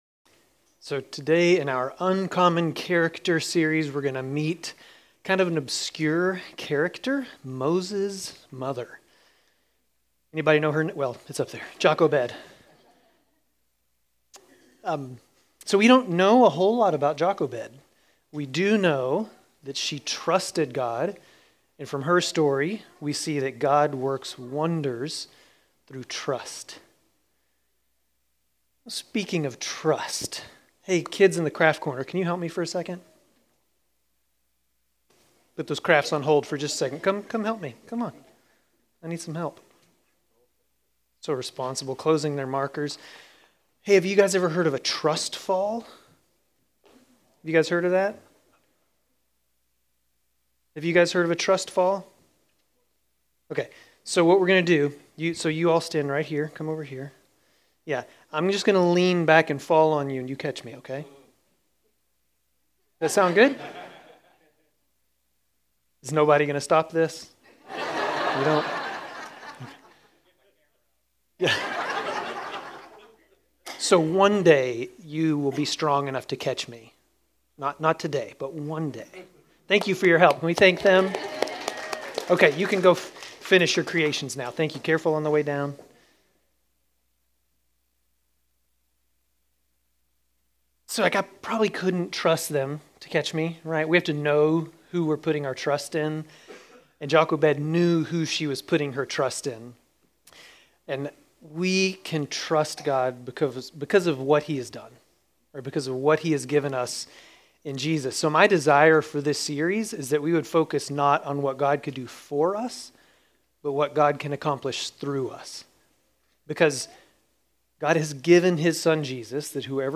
Grace Community Church Dover Campus Sermons 7_6 Dover Campus Jul 06 2025 | 00:28:31 1x 00:00 / 00:28:31 Subscribe Share RSS Feed Share Link Embed